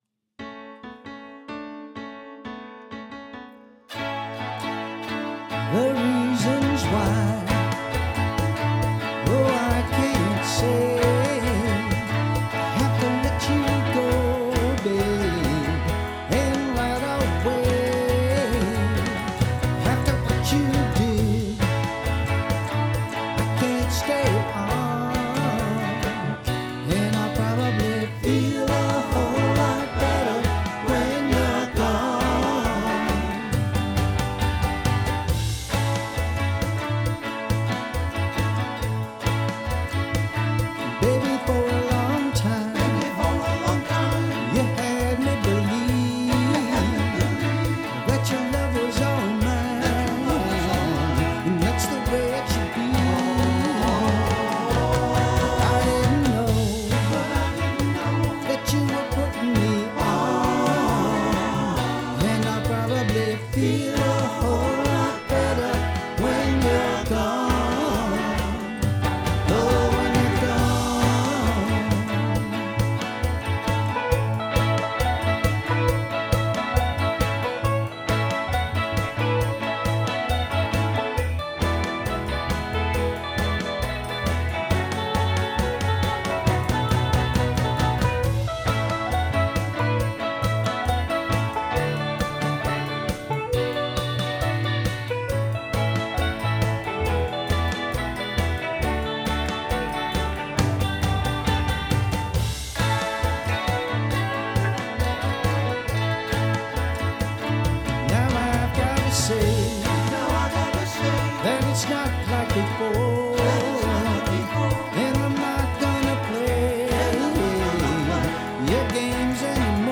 Lead Vocal) Recorded in Portland, ME
Lead/Rhythm Guitars, Piano, Bass, Background Vocals
Percussion and Drums